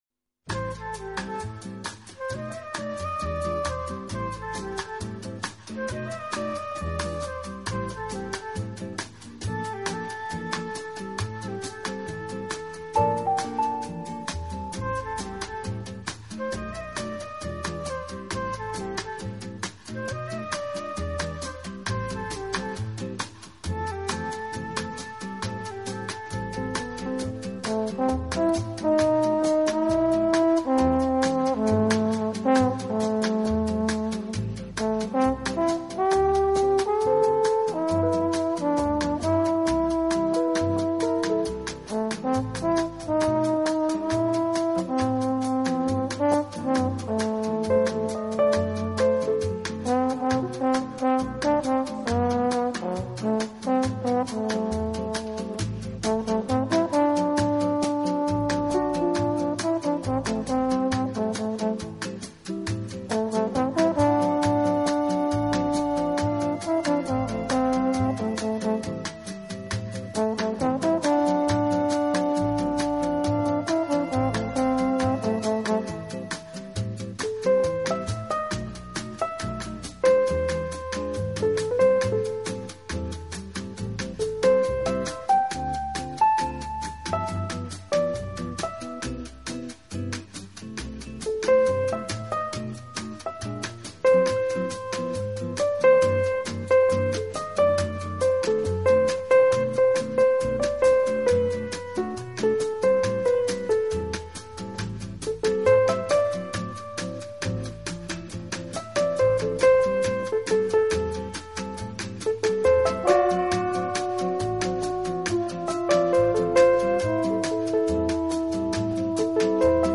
风格：Jazz